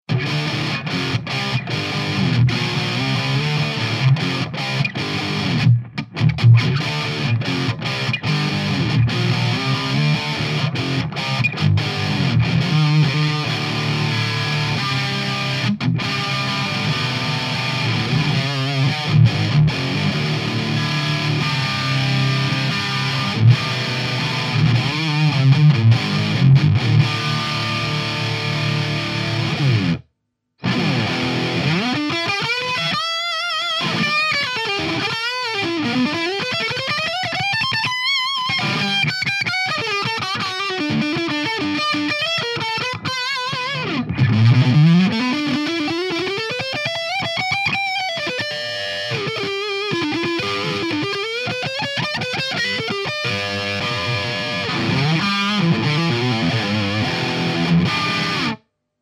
Poslal mi ukazku z vystupu slave + nahravku mikrofonom od bedne pri izbovej hlasitosti.
Prvy je cisty linkovy signal, druhe je mikrofon od bedne. ostatne su impulzy. podla nazvov tych impulzov by malo ist o JCM2000, Plexi a Mesa Boogie nejake: